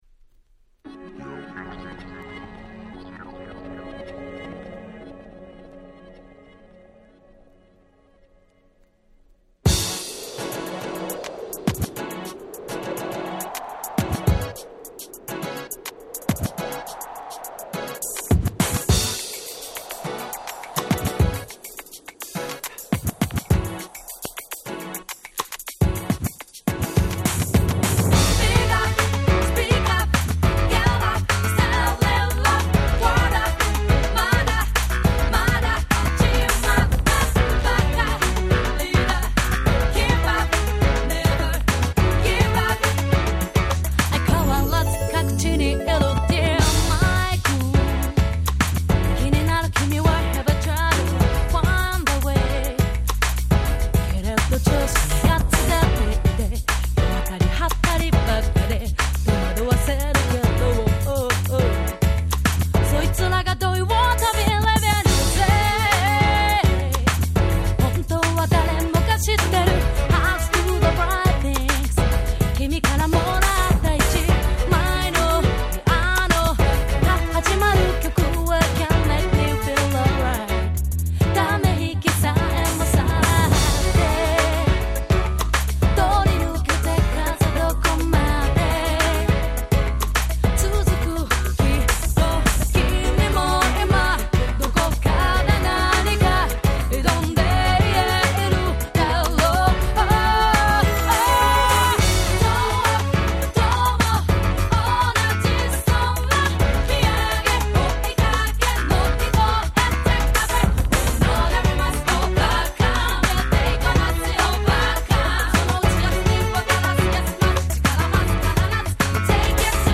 02' Smash Hit Japanese R&B !!